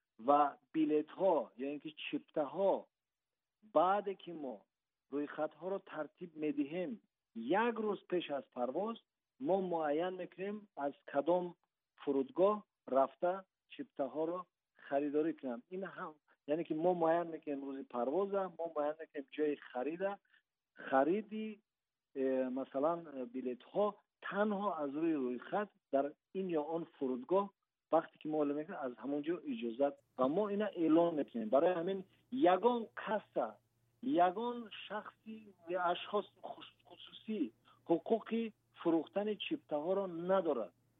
Сафири Тоҷикистон парвозҳои чартерӣ ба Русияро шарҳ дод